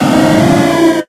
Cries
NOCTOWL.ogg